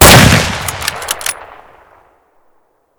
shoot_2.ogg